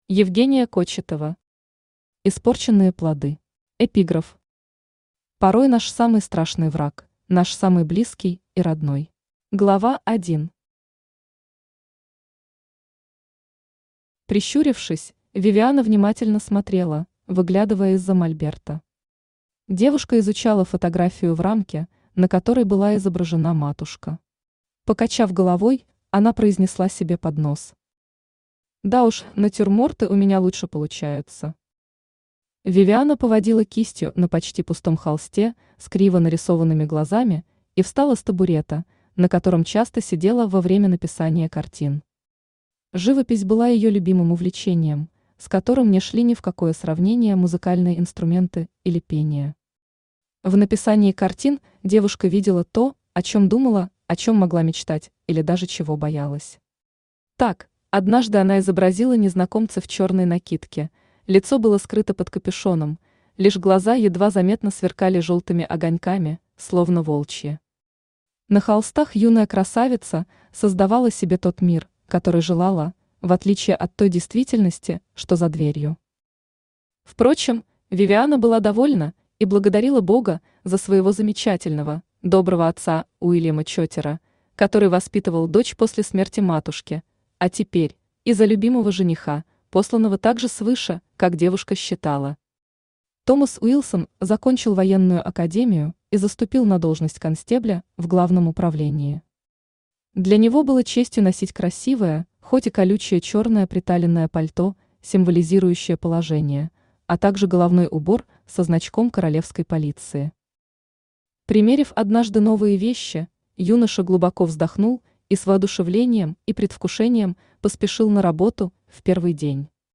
Аудиокнига Испорченные плоды | Библиотека аудиокниг
Aудиокнига Испорченные плоды Автор Евгения Олеговна Кочетова Читает аудиокнигу Авточтец ЛитРес.